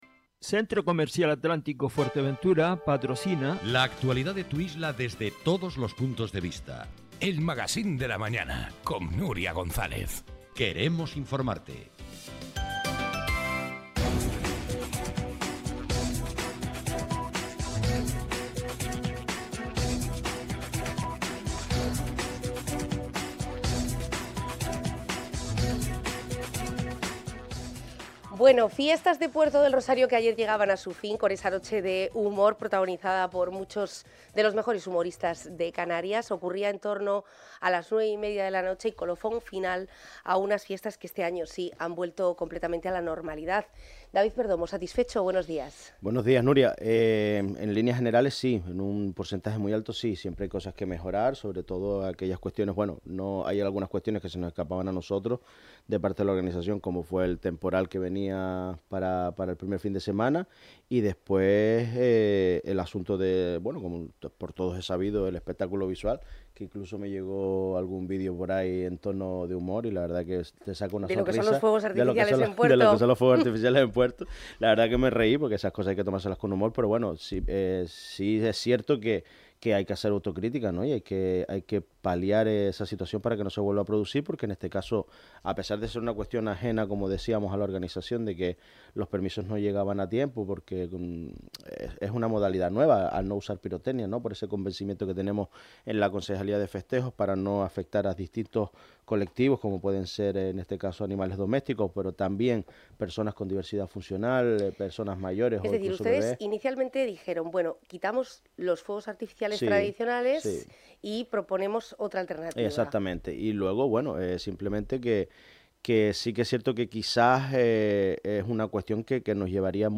Es el que ha hecho el concejal de Festejos del Ayuntamiento capitalino David Perdomo en El Magacín.